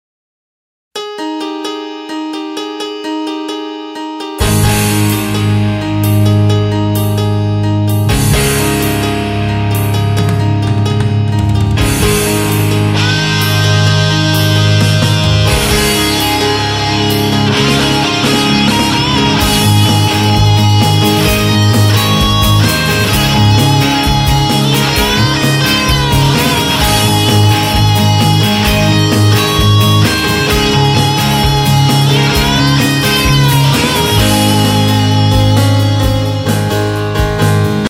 Drone when Recording Distorted Guitar
I've been playing around with micing and recording with an sm57/at2020. No matter what I do, I am unable to get a crisp sound without this drone around 130Hz.
I added some stereo imaging to one of them to give it some fullness.